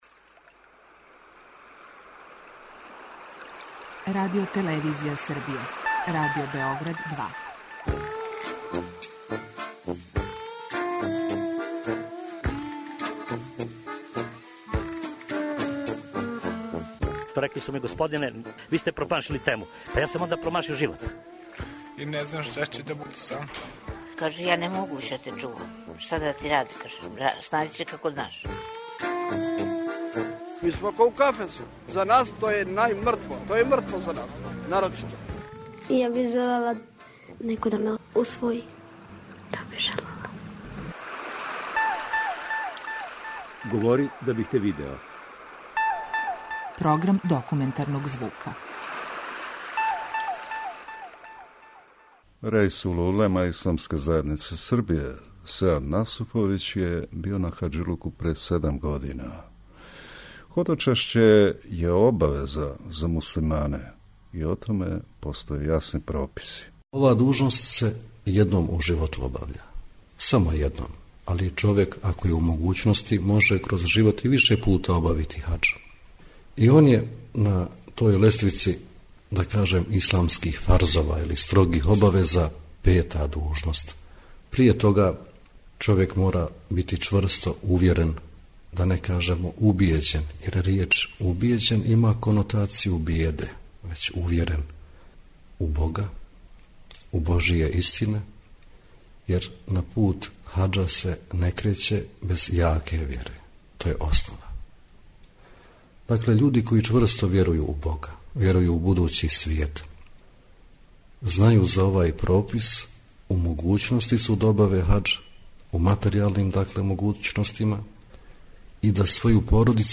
Документарни програм